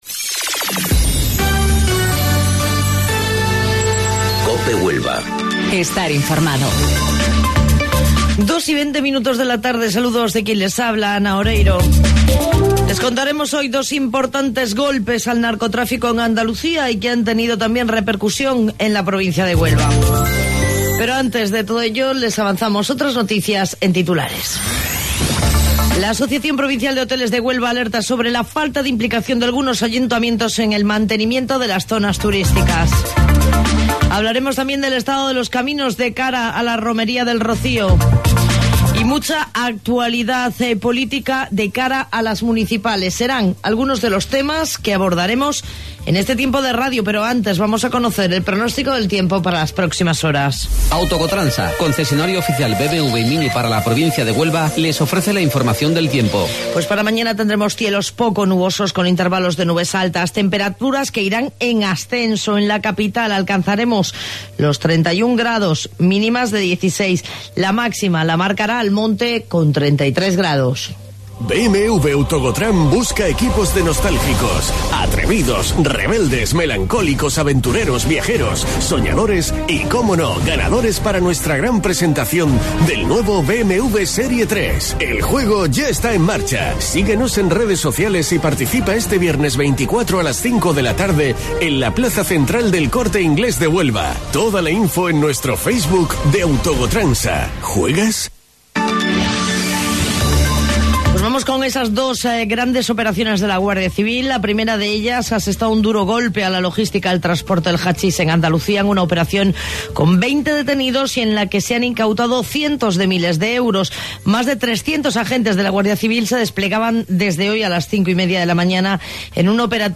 AUDIO: Informativo Local 14:20 del 1 de Mayo